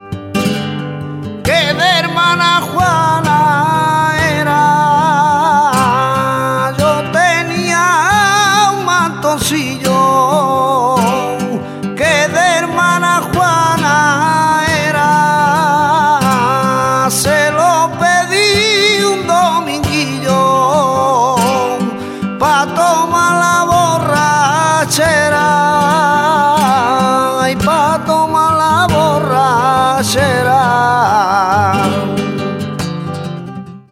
Estilo Primitivo (Viejo)